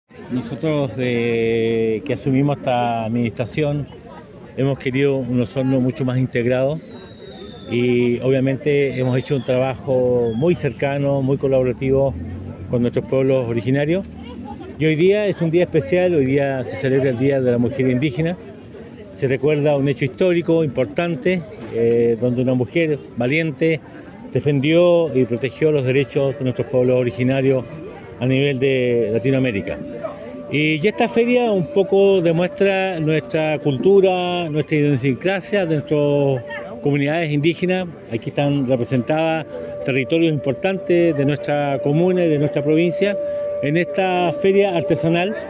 El Alcalde de Osorno, Emeterio Carrillo indicó que es muy importante dar el lugar que merecen los pueblos originarios, principalmente en lo que respecta a la equidad de género.